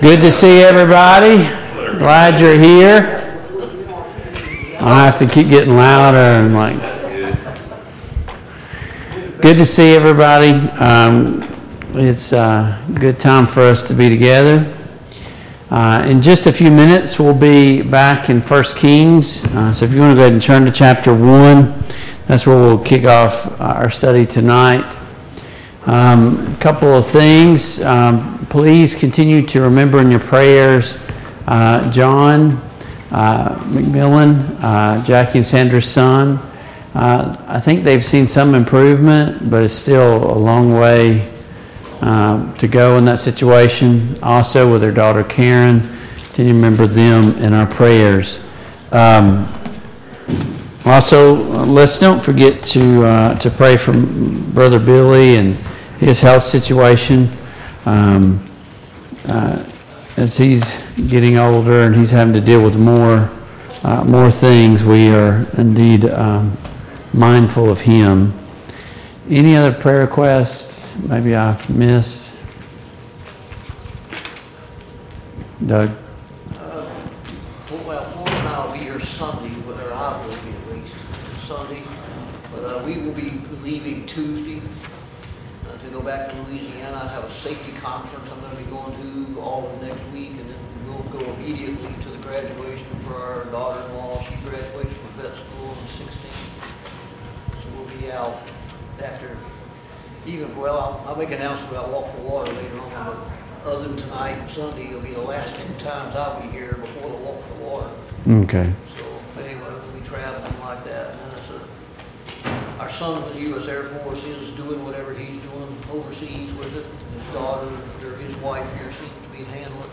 1 Kings 2 Service Type: Mid-Week Bible Study Download Files Notes Topics: King David , King Solomon « What is true Biblical Faith?